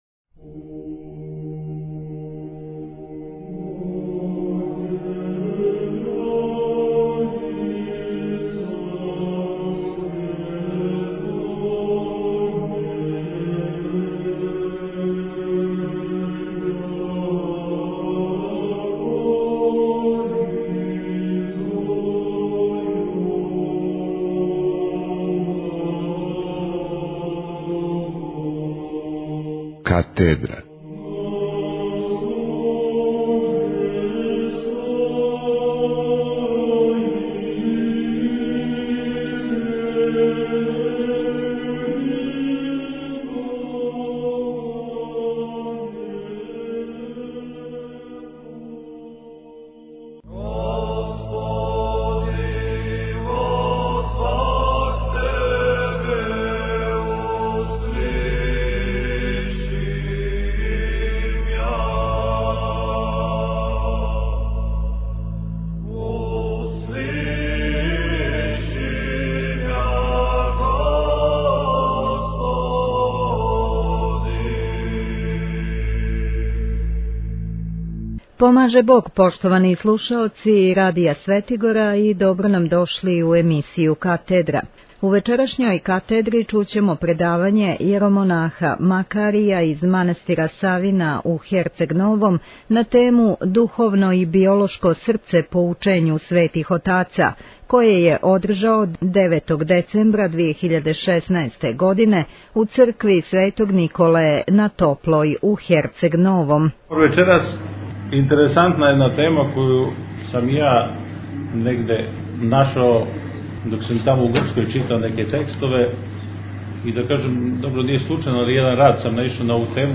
Предавање
у резиденцији Љубибратића на Топлој (црква Св. Николе)